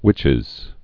(wĭchĭz)